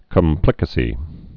(kəm-plĭkə-sē)